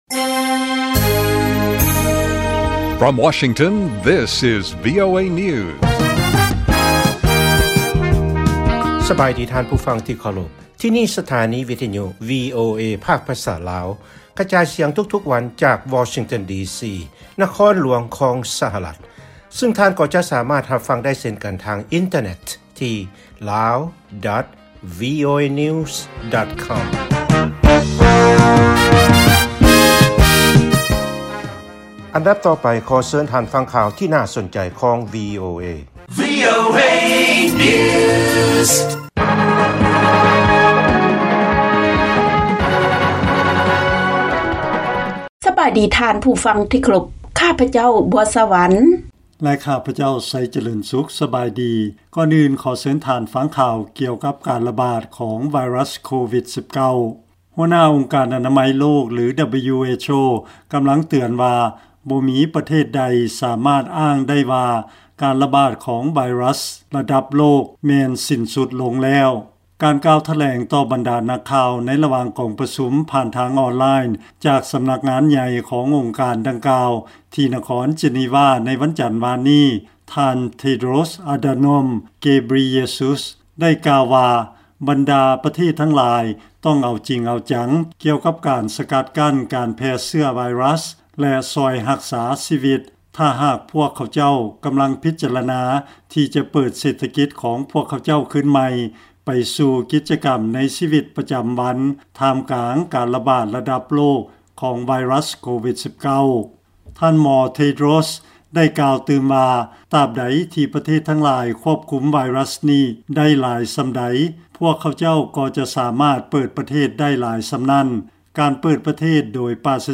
ລາຍການກະຈາຍສຽງຂອງວີໂອເອ ລາວ
ວີໂອເອພາກພາສາລາວ ກະຈາຍສຽງທຸກໆວັນ. ຫົວຂໍ້ຂ່າວສໍາຄັນໃນມື້ນີ້ມີ: 1) ຫົວໜ້າອົງການອະນາໄມໂລກ ຫຼື WHO ກ່າວວ່າ ໂລກ 'ບໍ່ສາມາດມາດອ້າງໄດ້ວ່າ ການລະບາດຊອງໂຣກ ໄດ້ສິ້ນສຸດແລ້ວ'.